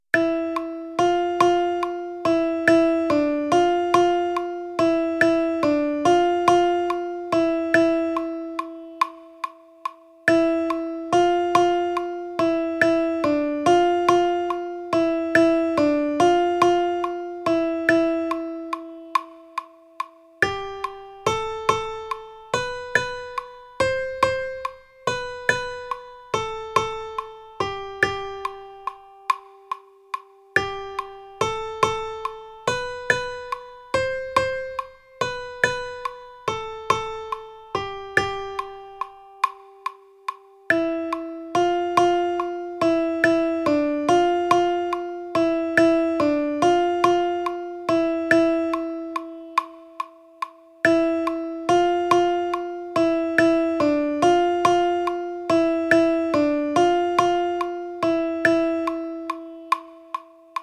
Arreglo para flauta dulce